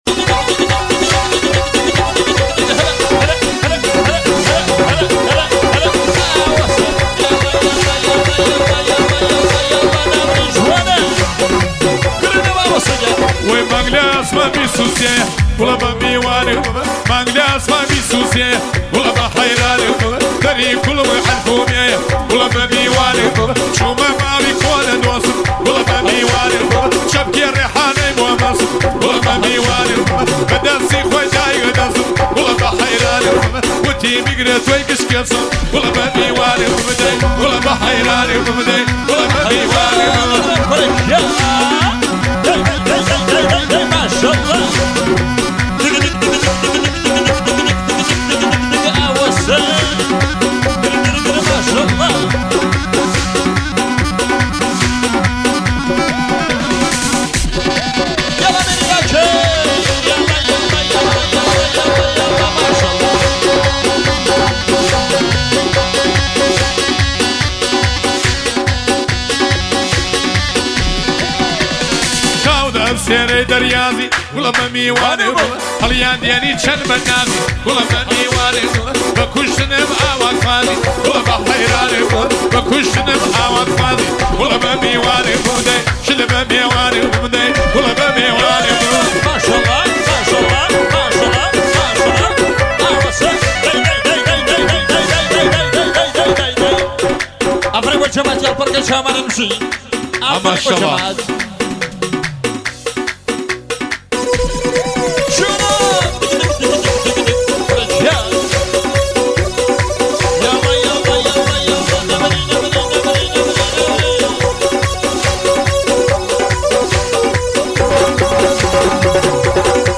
( اجرای مراسم ها )
آلبوم کردی
(مراسم های عروسی)